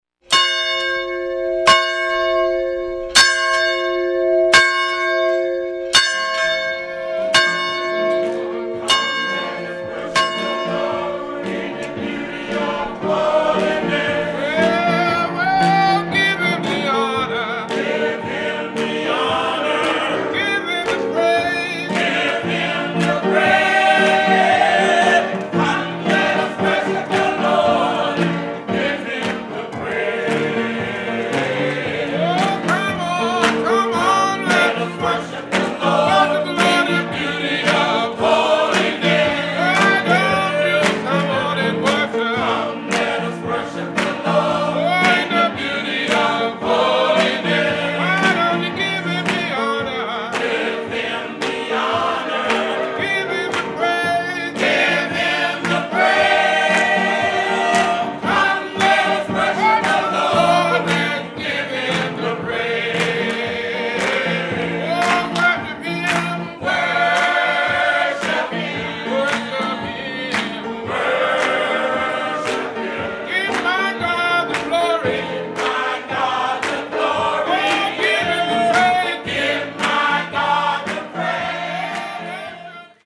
An Anthology of Rural Singing and Preaching Styles in African-American Churches of Southern Maryland
The singing styles of the featured choirs and individuals have flourished in a region where African-American communities date to the 1600s.
Now, at a time when neighboring churches have moved on to synthesized and electrified instrumental sounds, the music of Shiloh Community and Mt. Calvary United Methodist Churches remains strong and spirited with inspired piano and tambourine accompaniment, hand-clapping and a cappella singing.